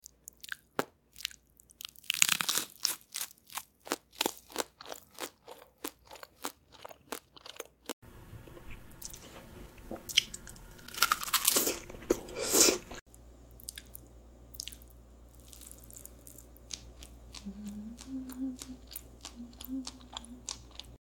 How to create food ASMR sound effects free download